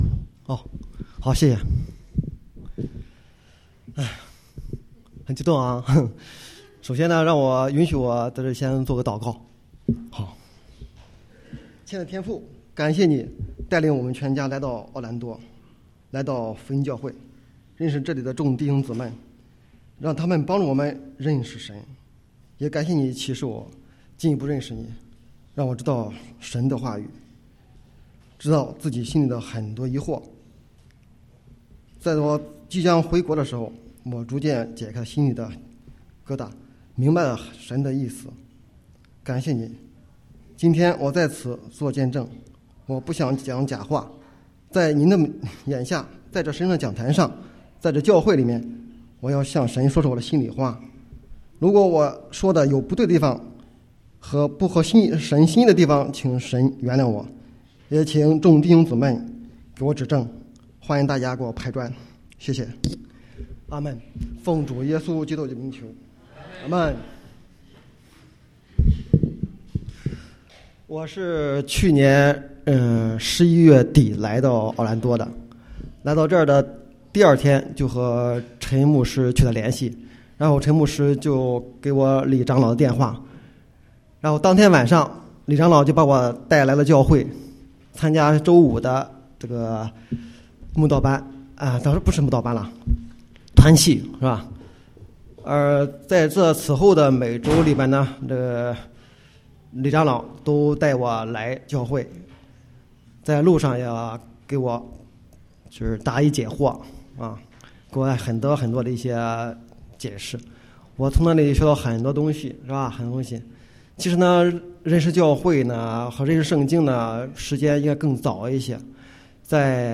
得救见证